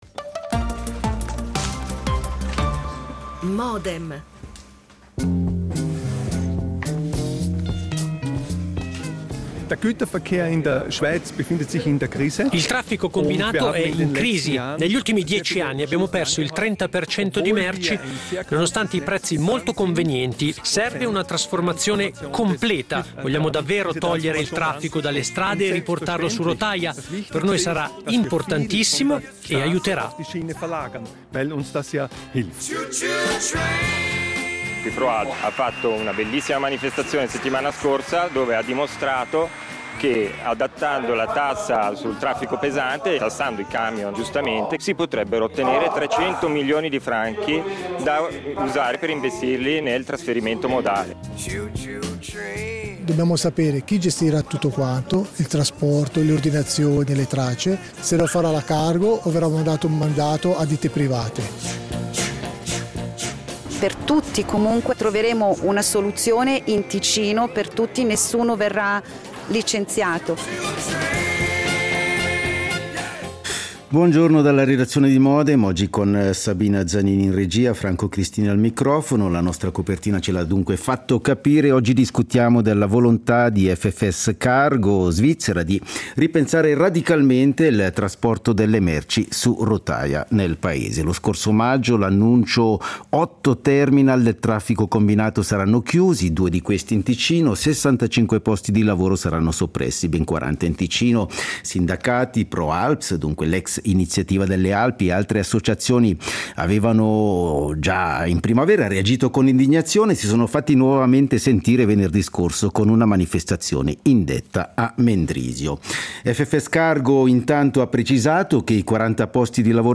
L'attualità approfondita, in diretta, tutte le mattine, da lunedì a venerdì